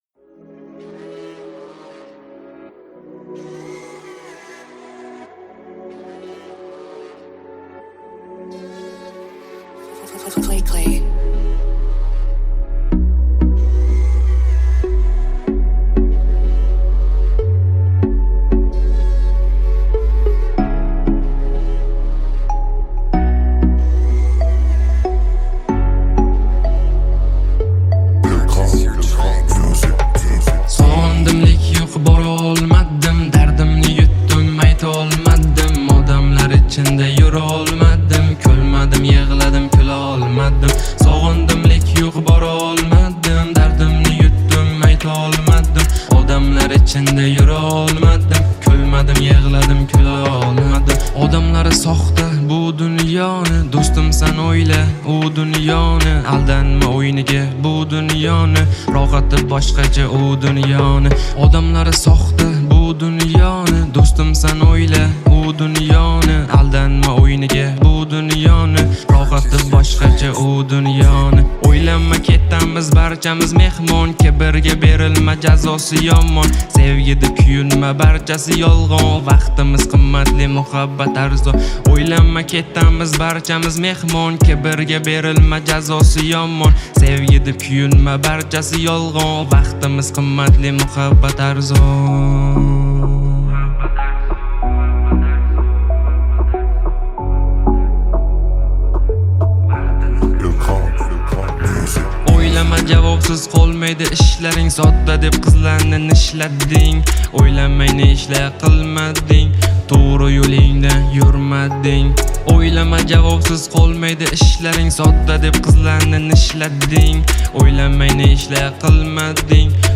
Скачать музыку / Музон / Фонк (Phonk)